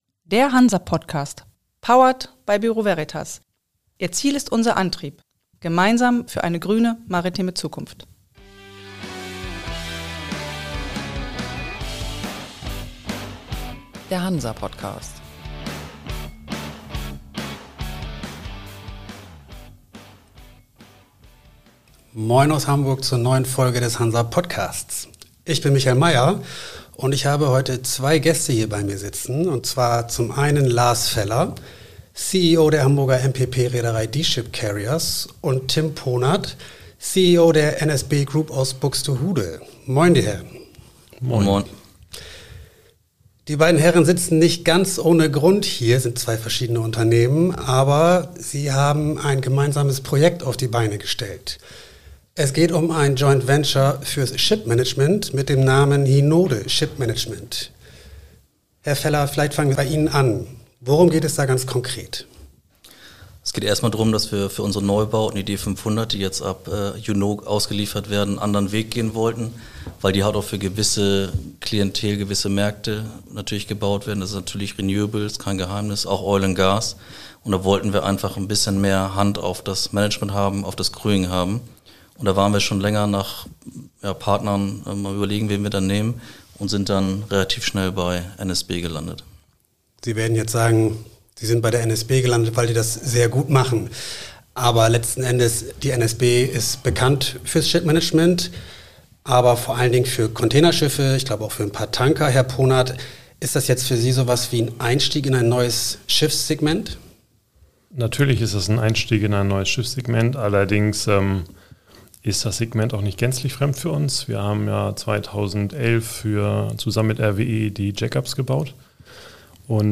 Das Gespräch geht aber auch weit über das neue Joint Venture hinaus.